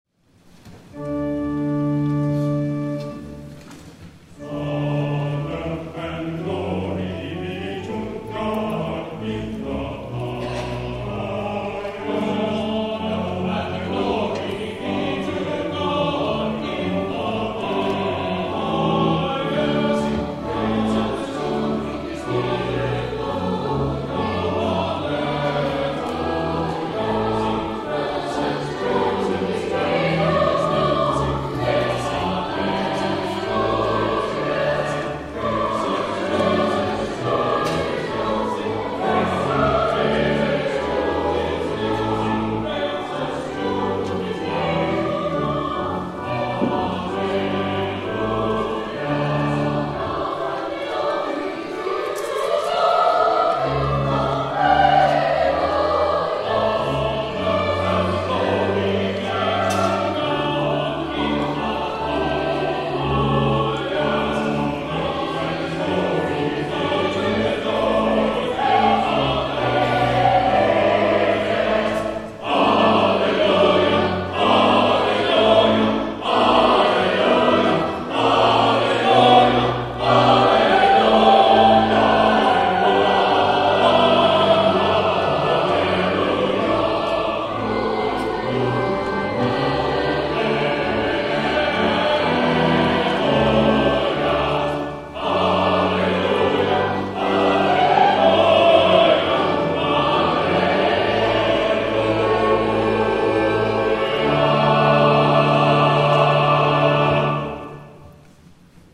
THE CHORAL OFFERTORY